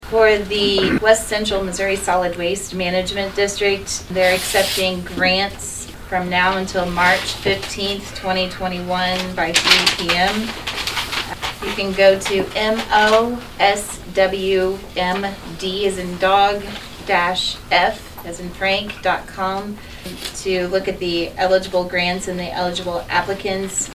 Saline County Northern District Commissioner Stephanie Gooden announced there is a grant opportunity available for some entities during the commission meeting on Thursday, February 4.